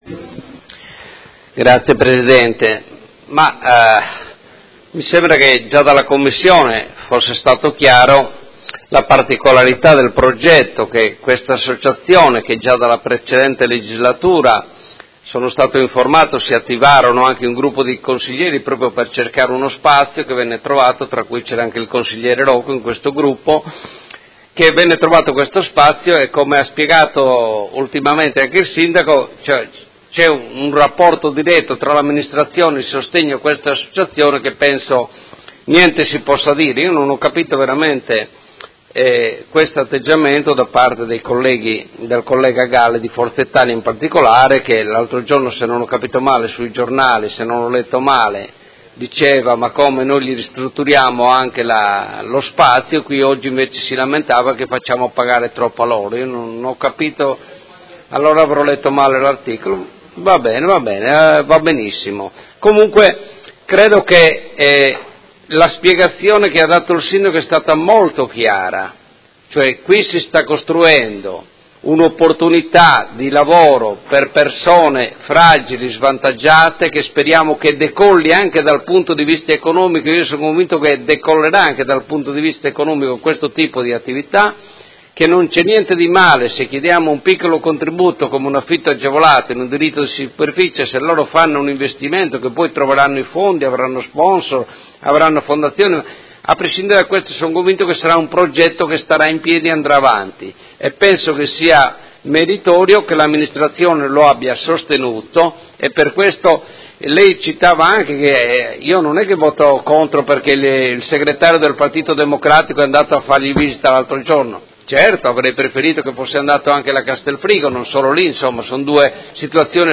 Marco Cugusi — Sito Audio Consiglio Comunale
Seduta del 23/11/2017 Dichiarazione di voto.